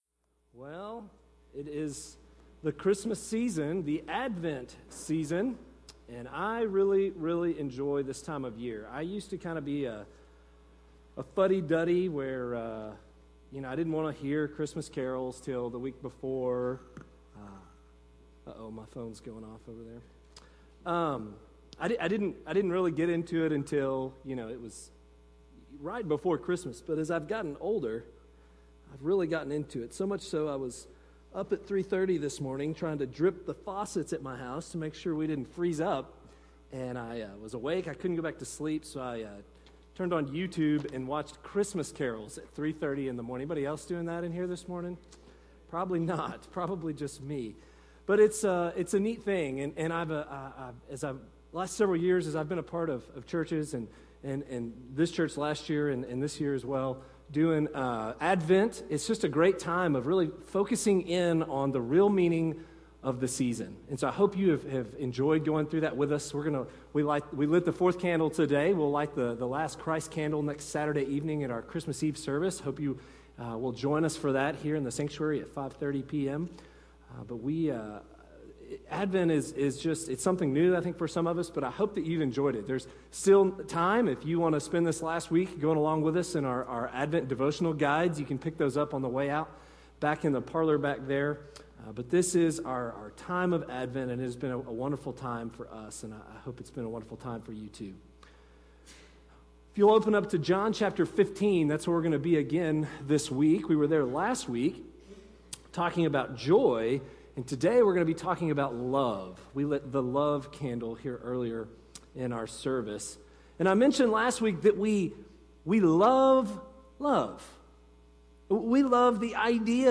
Sermons Archive - Page 29 of 35 - FBC Breckenridge